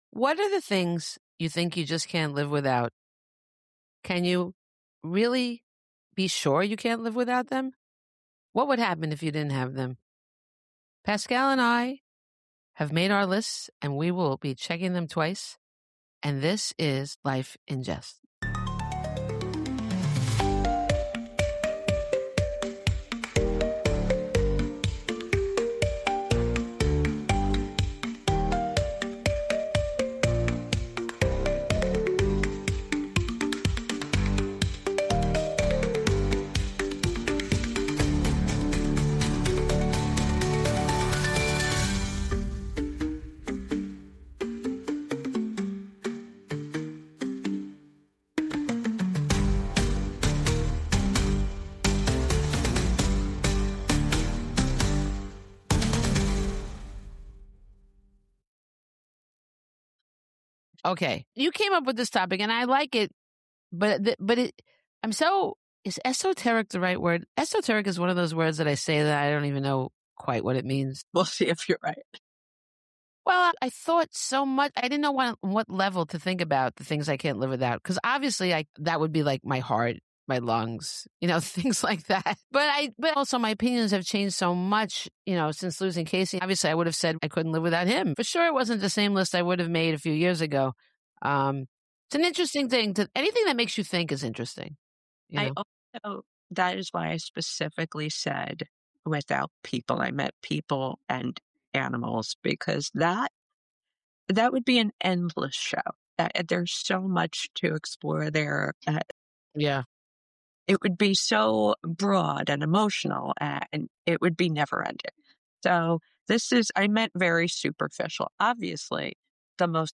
Along the way, the conversation spirals (as it always does) into deeper territory: grief, independence, aging, dating later in life, and the strange ways our priorities change over time.